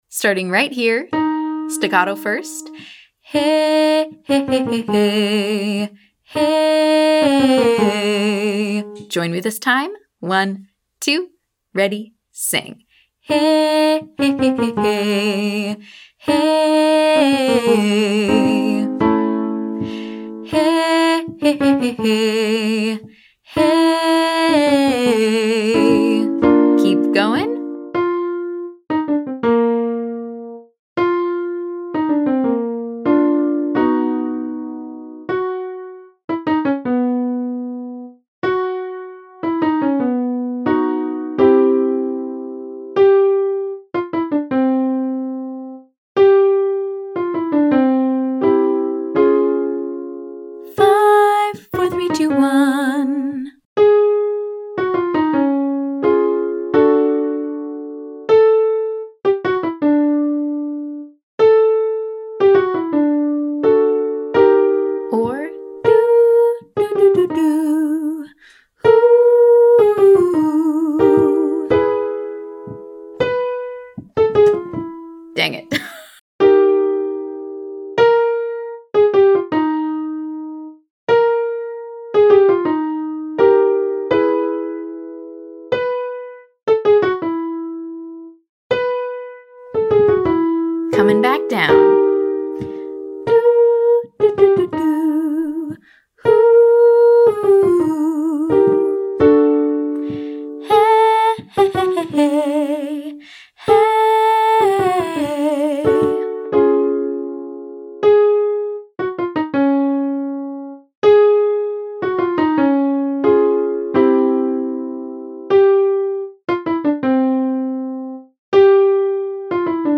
To ensure we’re singing all 3 pitches clearly, we’ll start staccato (short & detached), and then smooth it out.
Exercise 1: 5–43 x2 (staccato, legato)
Beginner Riffs And Runs For High Voices 3B